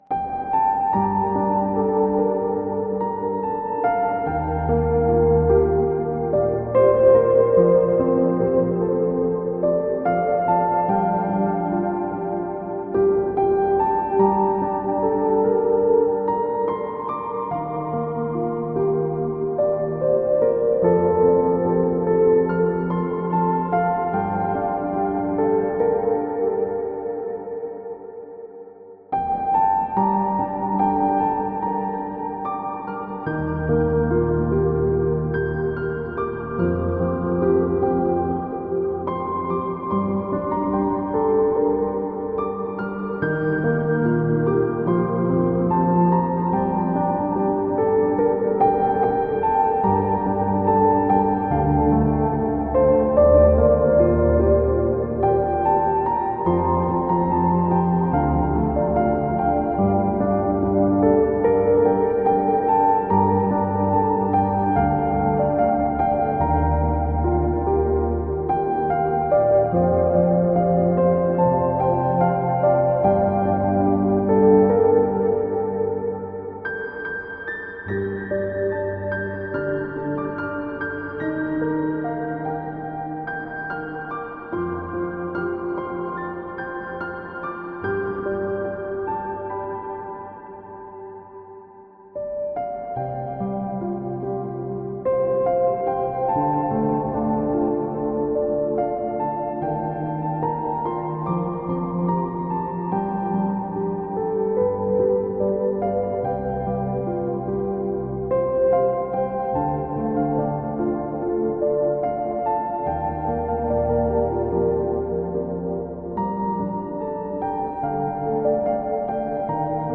A Piano